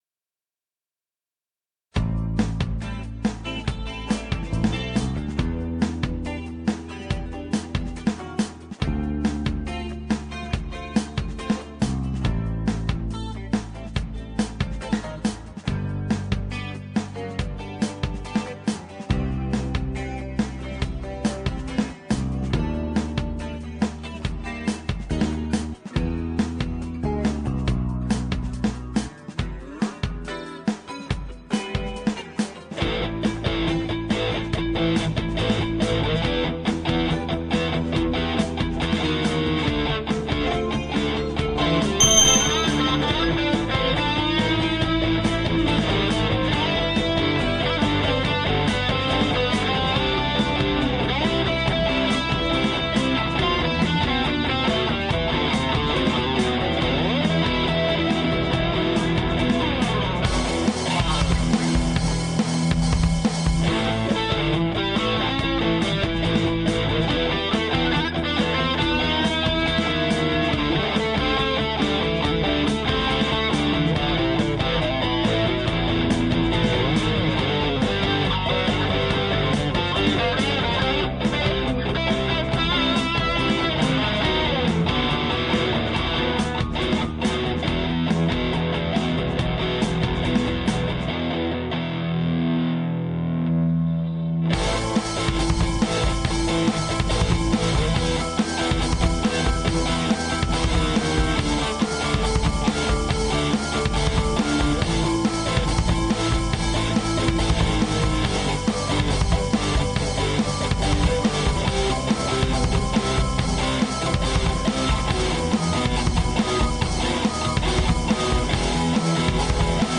Покупал диски с гитарными нарезками, что то стыковал, а это после покупки Aria pro II... да еще! преамп то ламповый... вау, адреналин шкалил, улыбаемся...